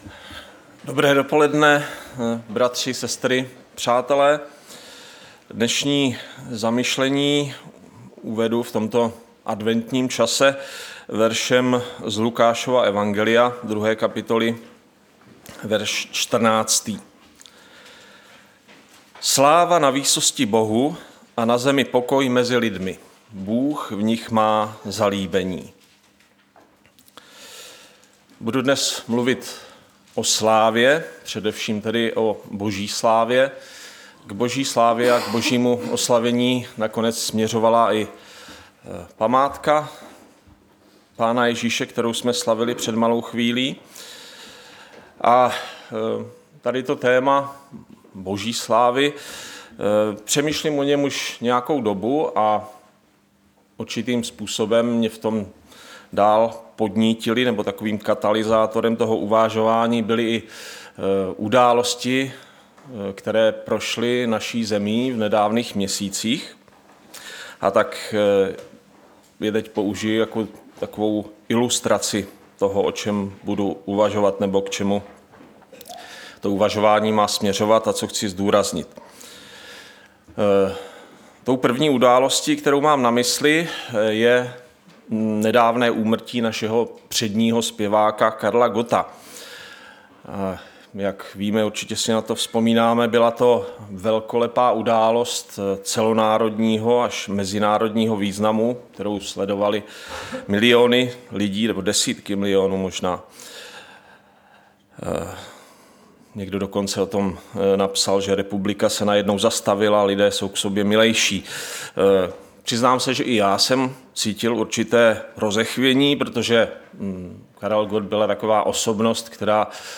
Nedělní vyučování